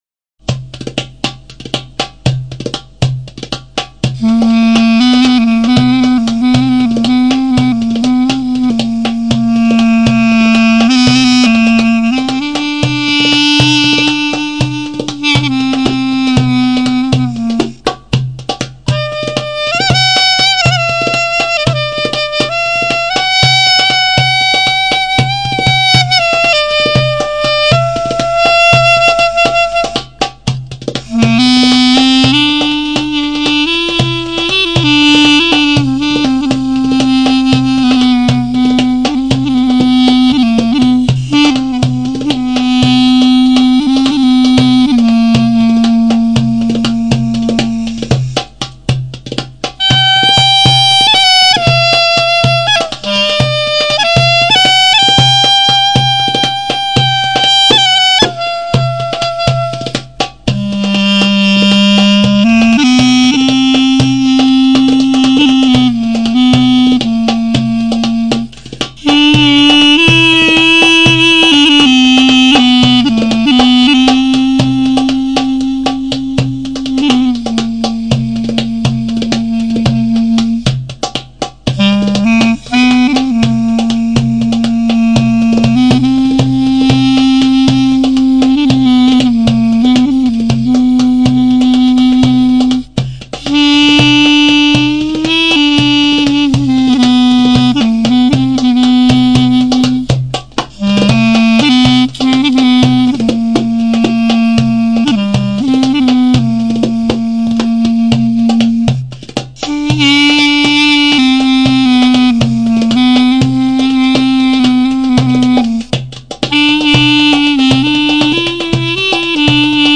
klarina
clarinete de PVC de diámetro interior de 16 mm